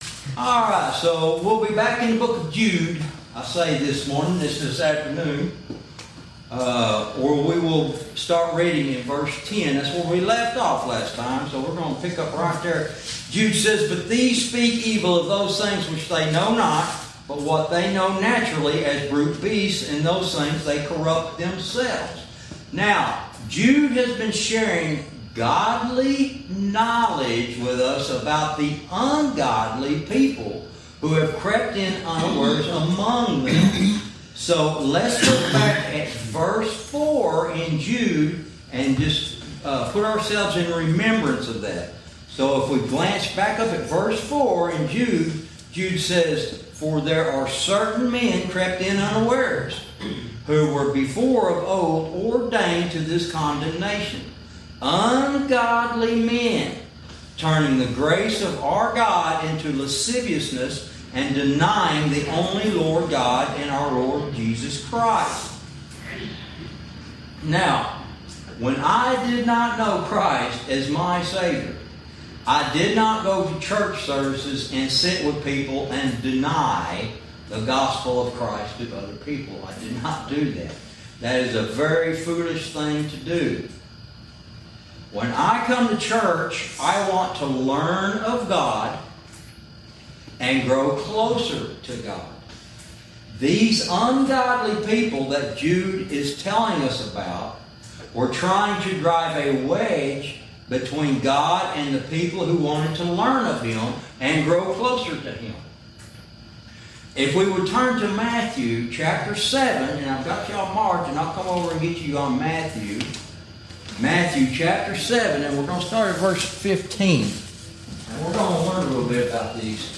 Verse by verse teaching - Lesson 37